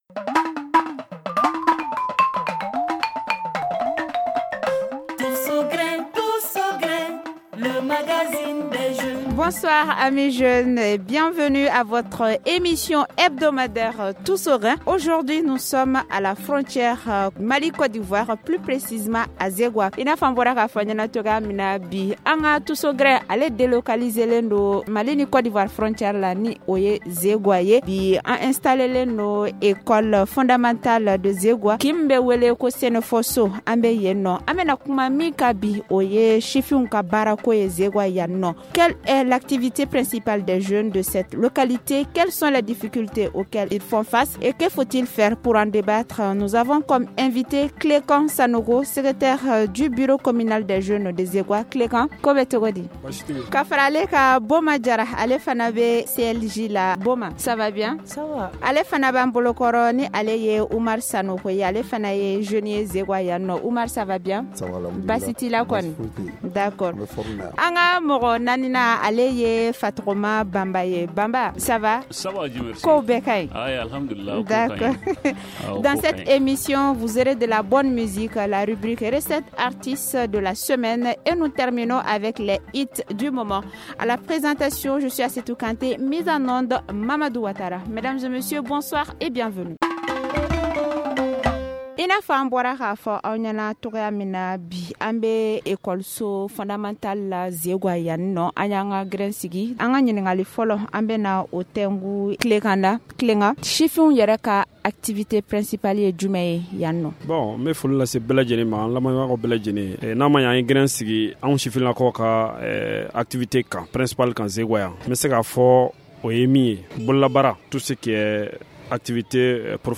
“Le Tous au grin” de ce soir est délocalisé dans le cercle de Kadiolo plus précisément dans la commune de Zégoua. Dans la commune, des jeunes déplorent le chômage qui sévit dans la localité.